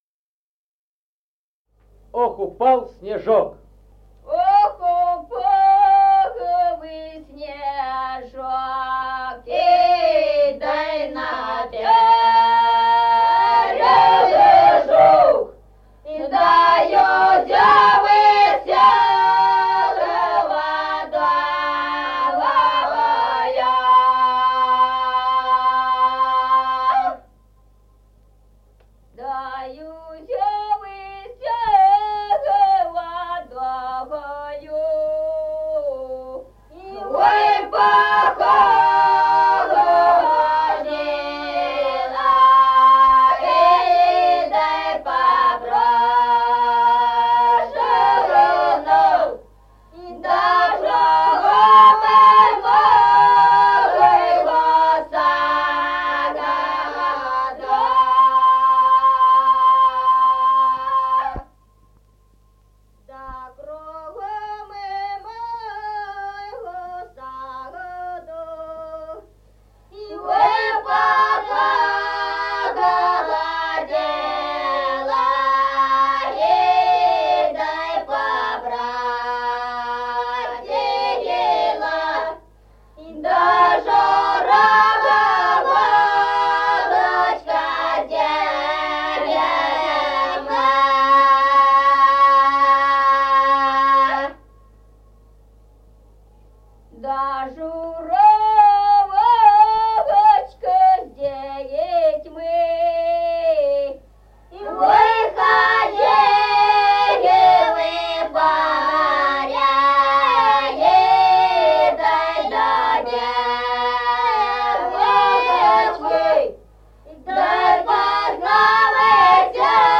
Народные песни Стародубского района «Ох, упал снежок», лирическая.
с. Мишковка.